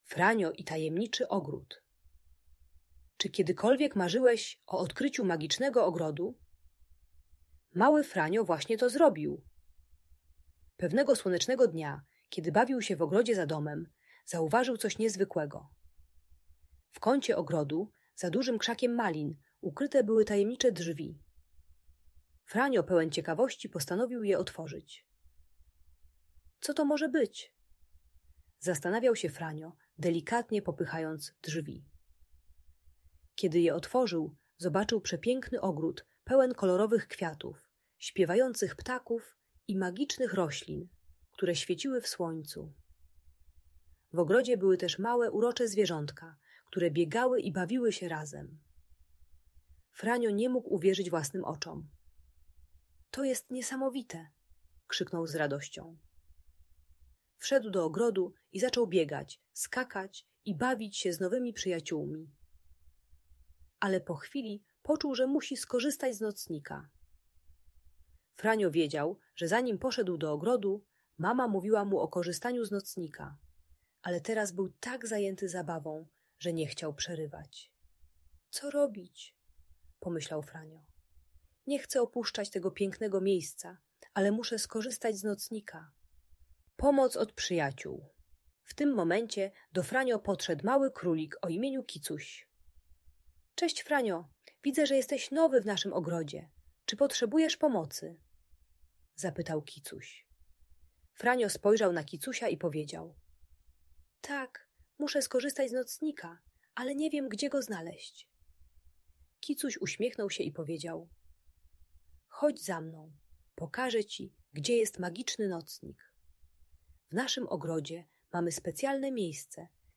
Franio i Tajemniczy Ogród: Magiczna Story - Trening czystości | Audiobajka